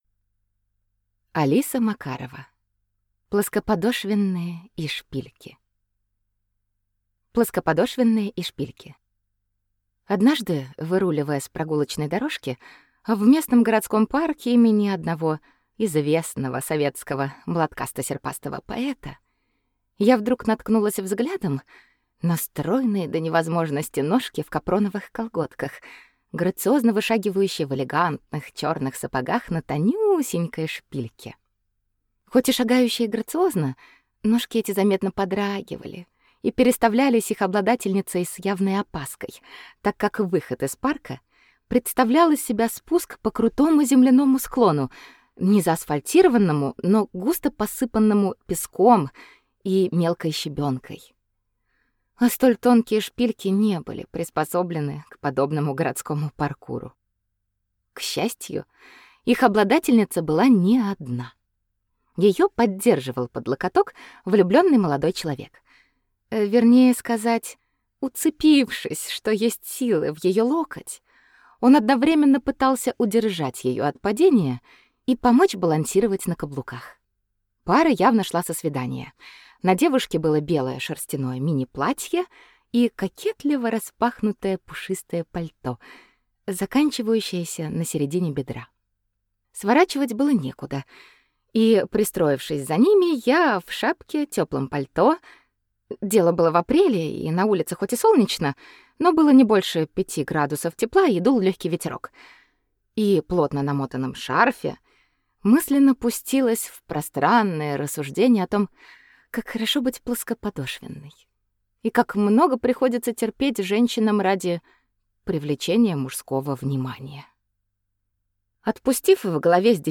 Аудиокнига Плоскоподошвенные и шпильки | Библиотека аудиокниг
Прослушать и бесплатно скачать фрагмент аудиокниги